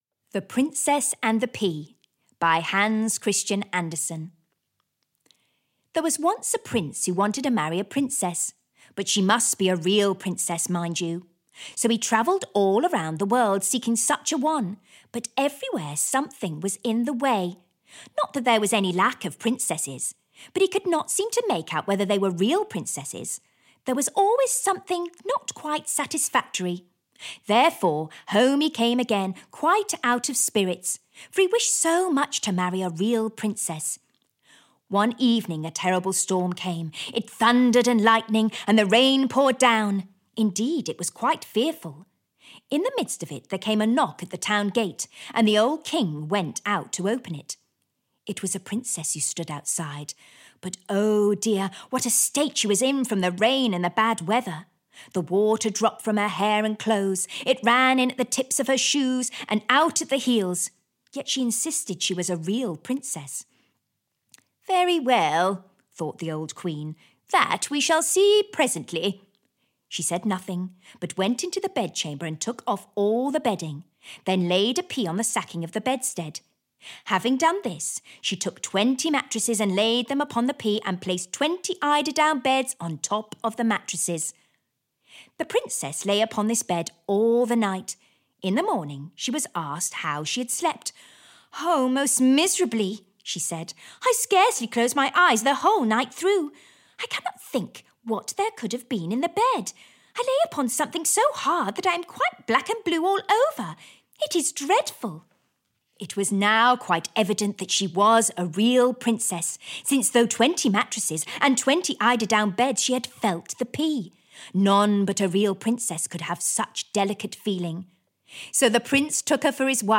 The Fairy Tale Book (EN) audiokniha
Ukázka z knihy